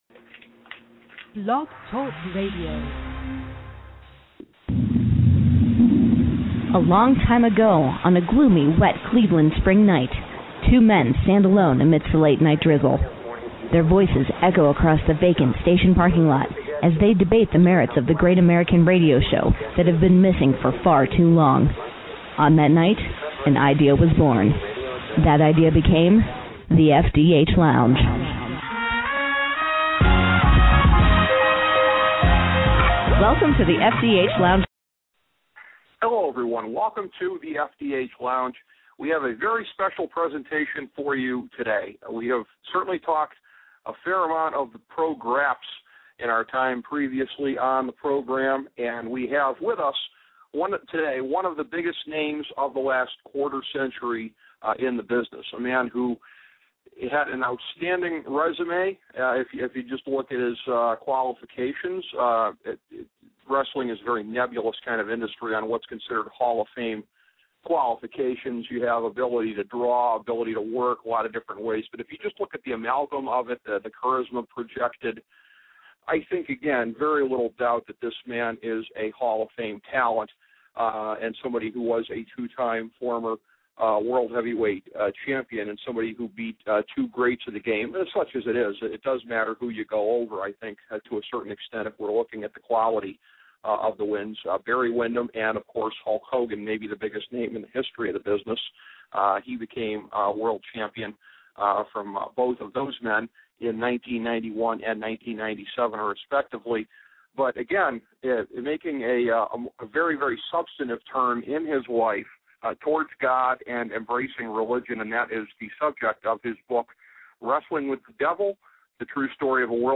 A conversation with Lex Luger
In another appearance on the Sportsology channel, The FDH Lounge sits down with wrestling legend, former world champion and author Lex Luger.